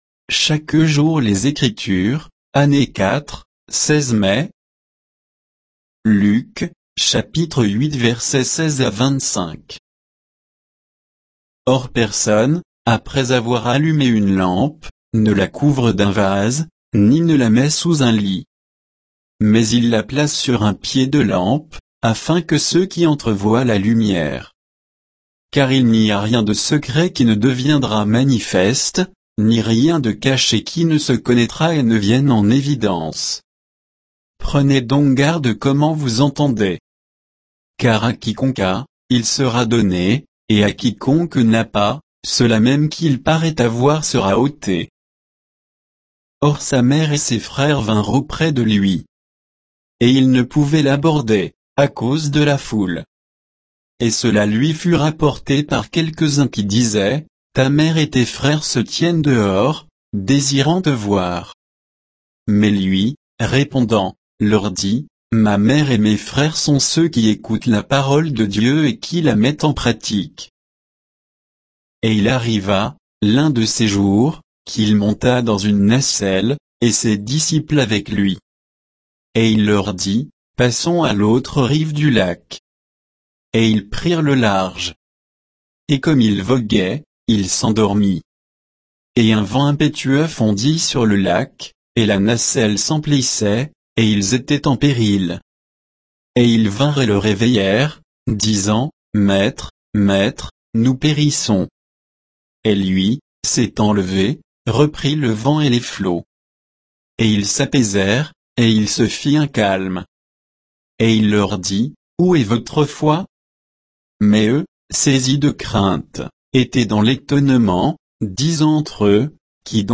Méditation quoditienne de Chaque jour les Écritures sur Luc 8